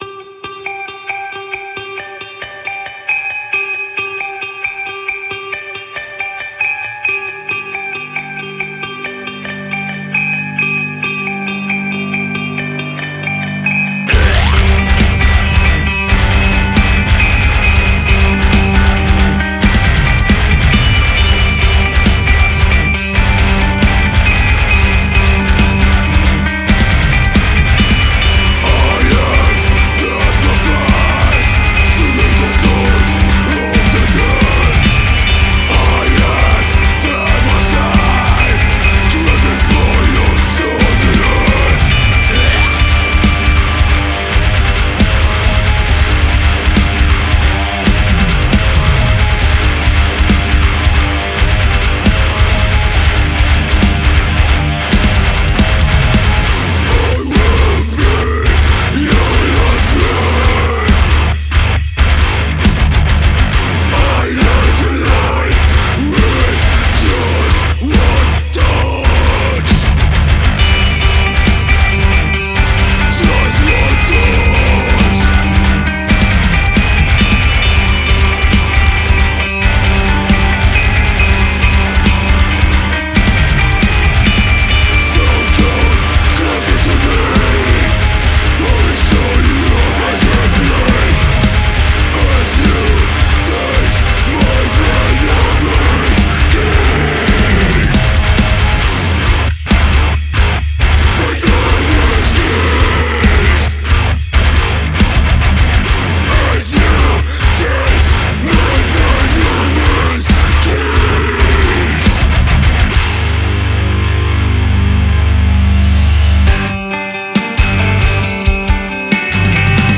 Gatunek: Deathcore/Djent/groove